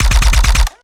Added more sound effects.
GUNAuto_RPU1 B Burst_02_SFRMS_SCIWPNS.wav